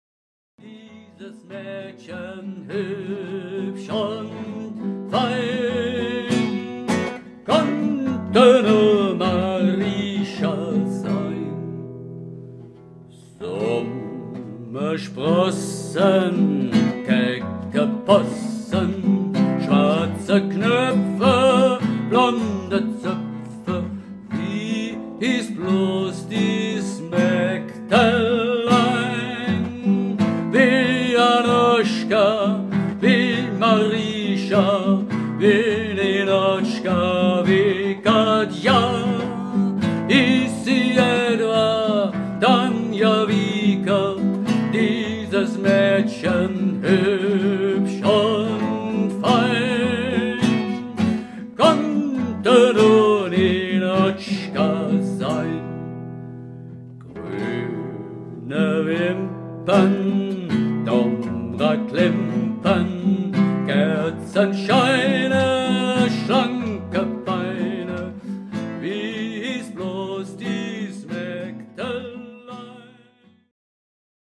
Gruppenfassung